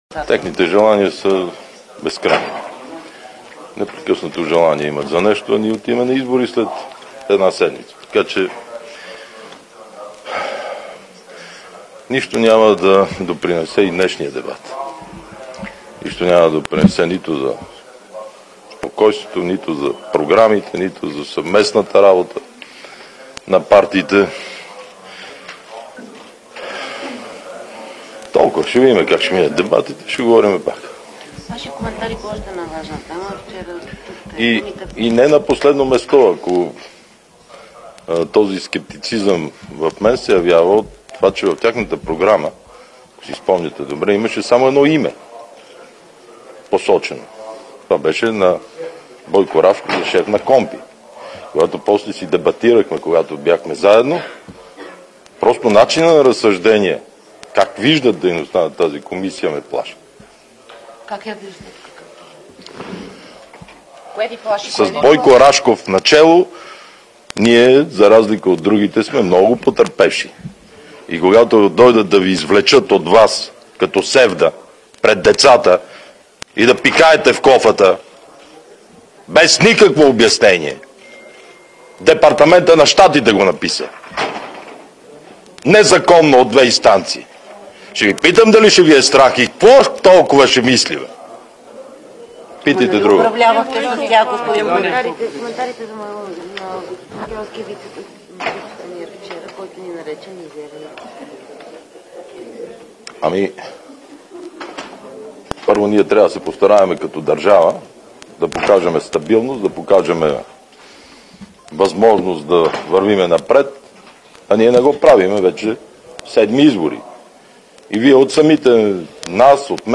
9.05 - Заседание на Народното събрание.
- директно от мястото на събитието (Народното събрание)